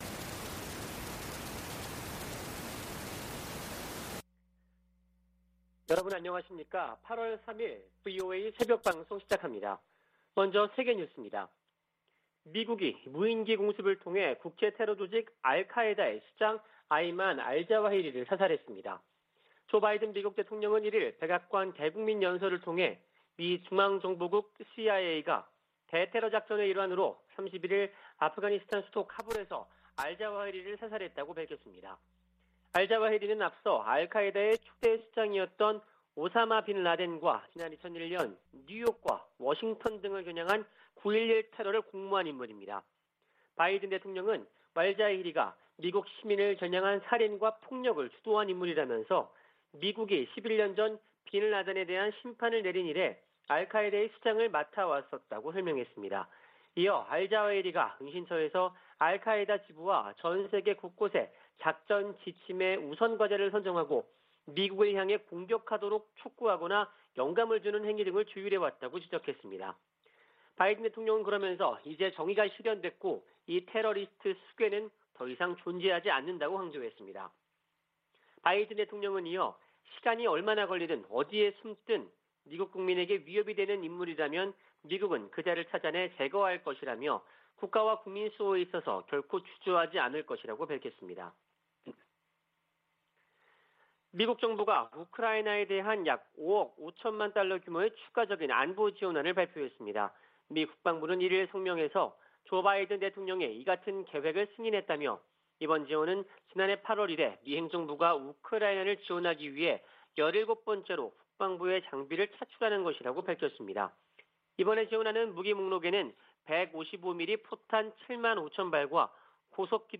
VOA 한국어 '출발 뉴스 쇼', 2022년 8월 3일 방송입니다. 조 바이든 미국 대통령은 핵확산금지조약(NPT) 평가회의를 맞아 비확산 체제 준수와 지지 입장을 재확인했습니다. 토니 블링컨 미 국무장관은 NPT 평가회의에서, 조약이 가중되는 압박을 받고 있다며 북한과 이란의 핵 개발 문제를 비판했습니다.